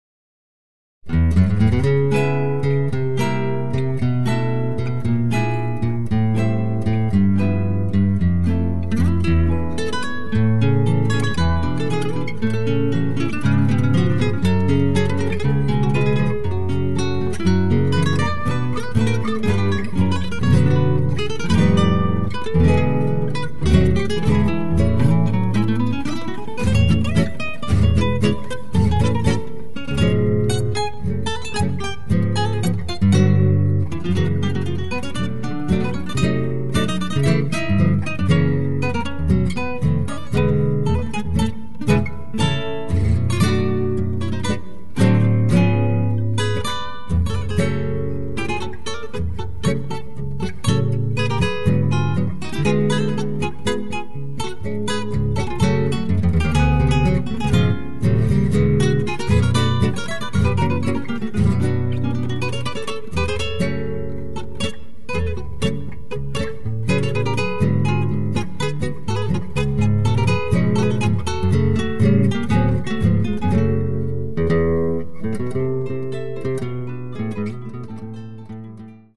tango